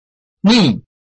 拼音查詢：【饒平腔】ni ~請點選不同聲調拼音聽聽看!(例字漢字部分屬參考性質)